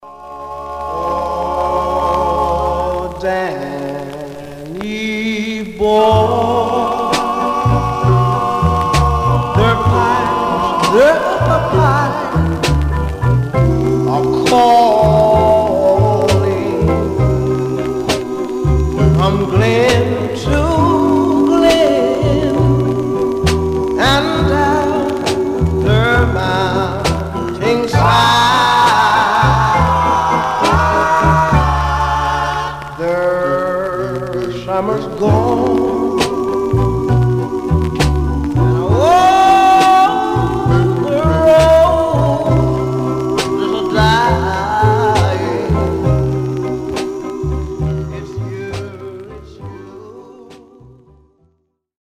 Much surface noise/wear Stereo/mono Mono
Male Black Groups